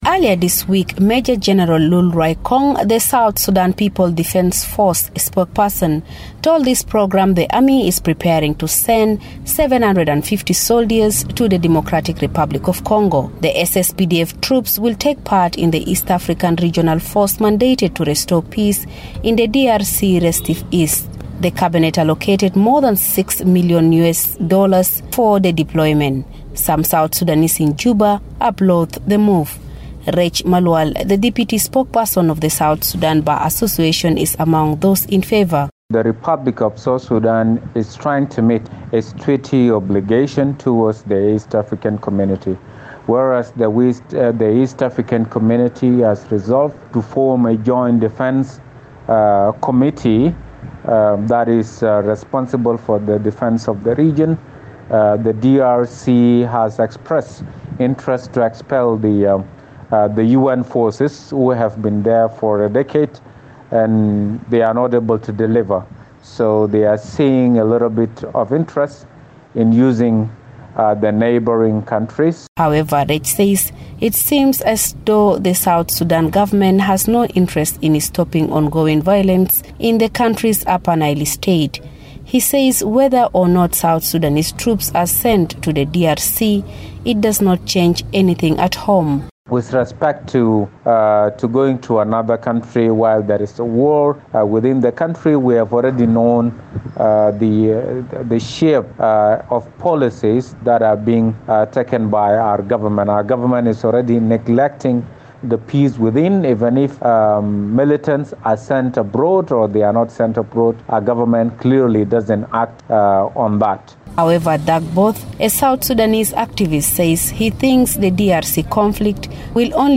from Juba.